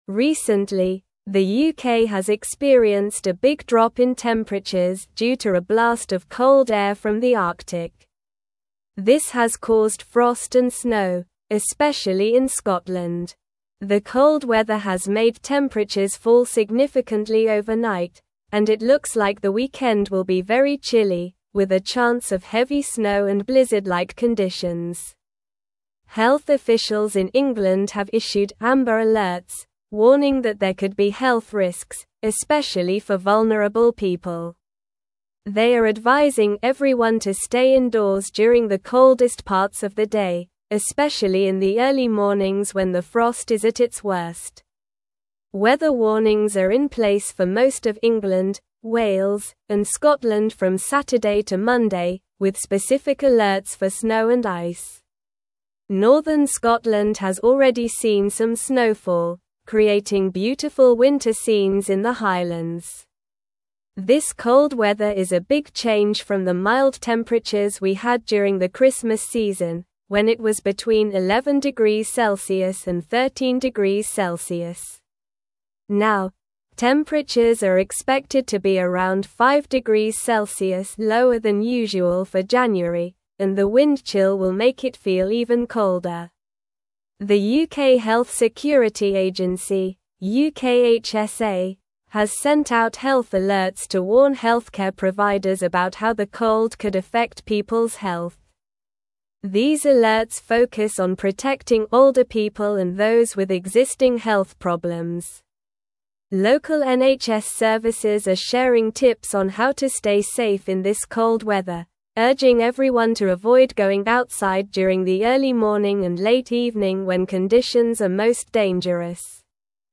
Slow
English-Newsroom-Upper-Intermediate-SLOW-Reading-UK-Faces-Bitter-Cold-and-Heavy-Snow-This-Weekend.mp3